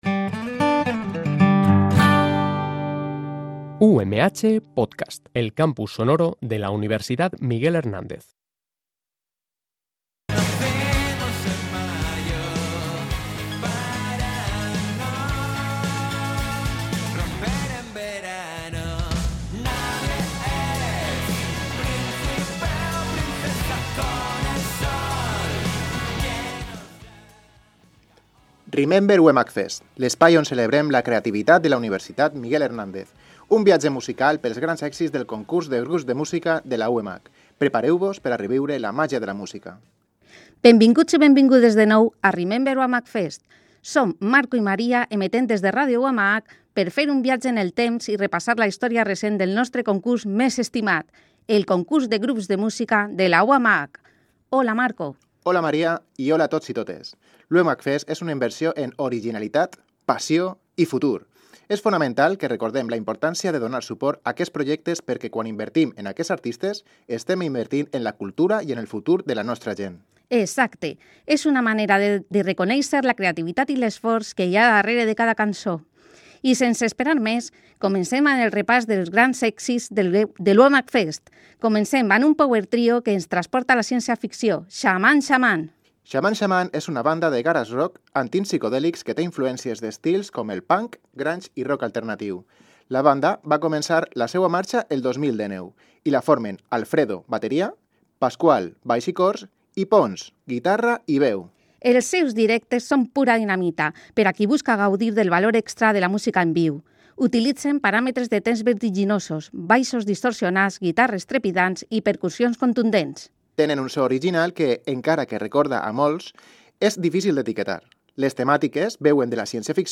El programa es un viatge musical pels grans èxits del Concurs de Grups de Música de la UMH que repassa la història recent d’aquest concurs.
En aquesta edició, escoltem les bandes més destacades que han participat en el Concurs de Grups de Música de la UMH: Sr. Bizarro, Khëlleden, Shaman Shaman, Elsa Grande, Tape on Sale, La 126 i Becarios.